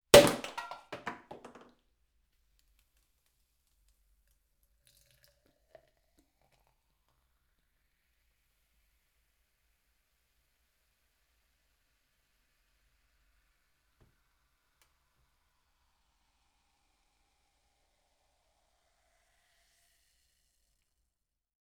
Champagne Bottle Uncork Sound
household